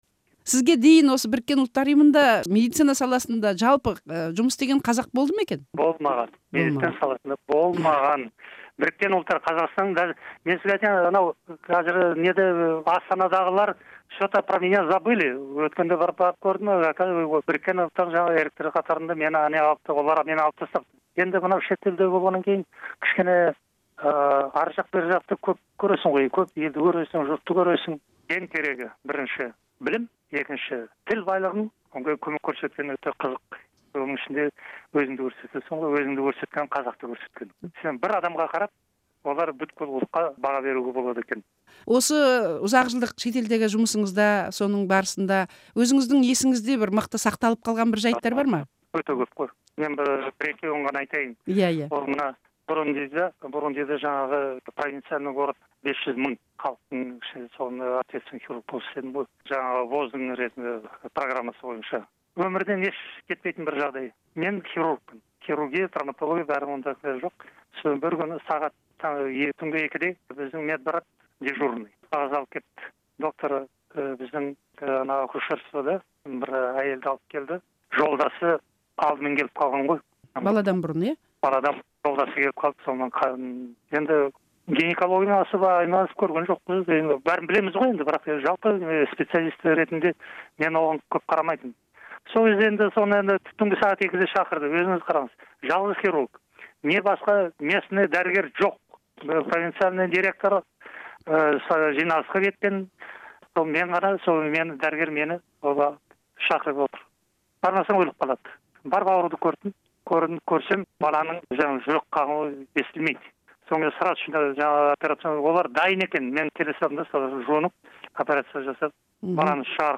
сұқбаты